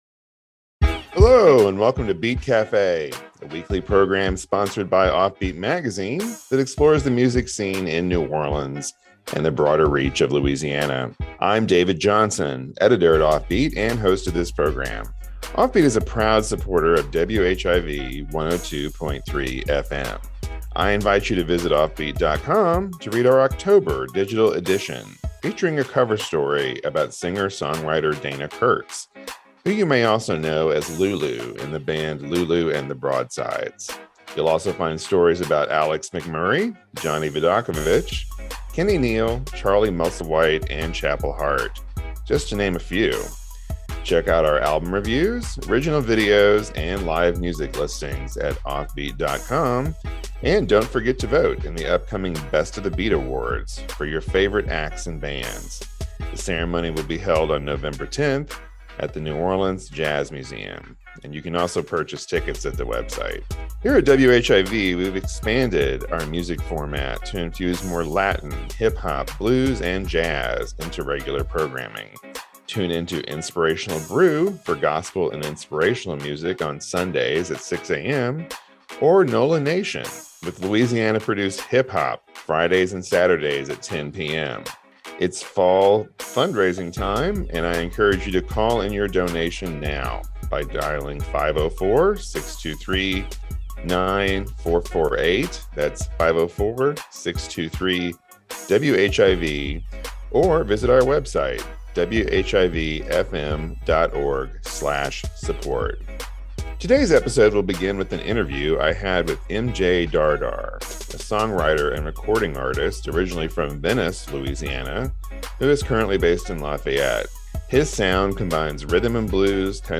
This week’s episode featured an interview with Lafayette-based musician